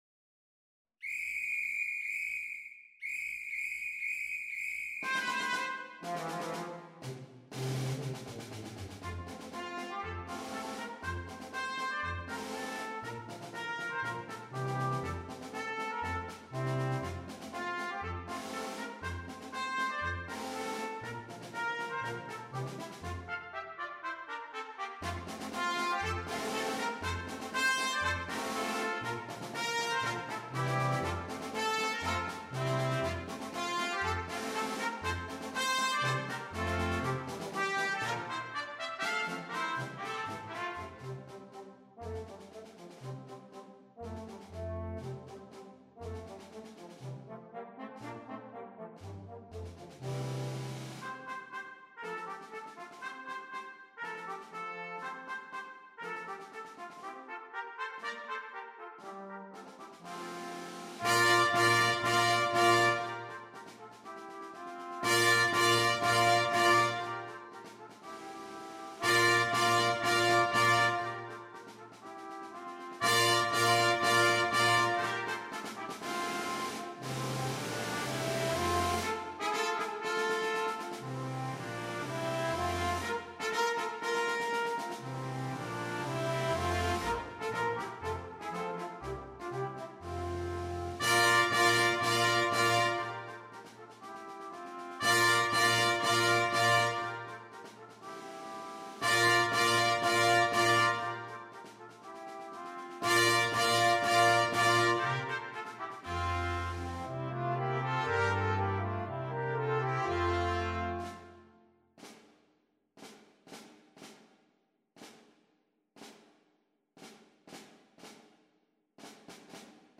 is a spirited march for brass quintet